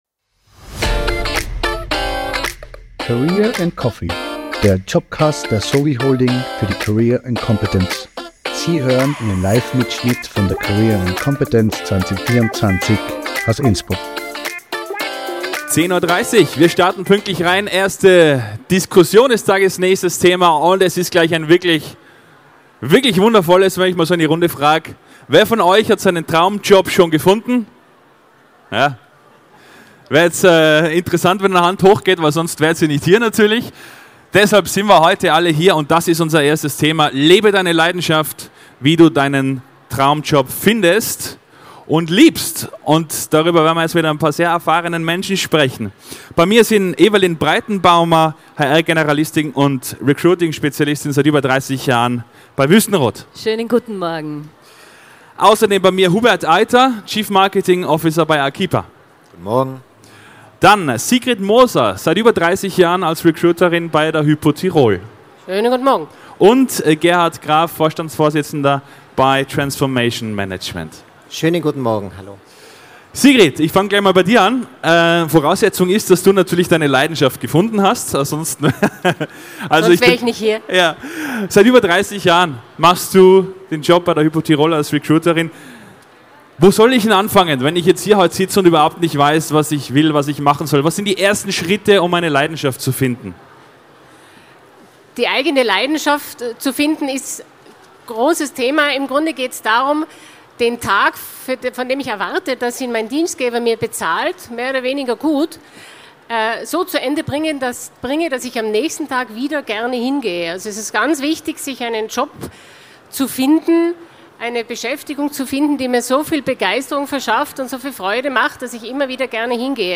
Livemitschnitt von der career & competence am 24. April 2024 im Congress Innsbruck.